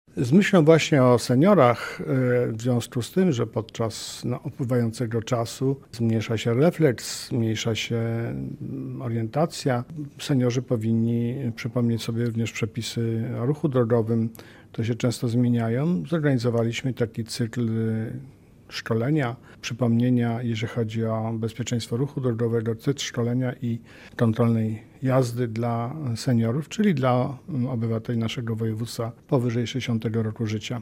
Chcemy zadbać o bezpieczeństwo w ruchu drogowym, ale również dać możliwość doświadczonym kierowcom, aby przypomnieć zasady ruchu drogowego – mówi marszałek województwa mazowieckiego, Adam Struzik.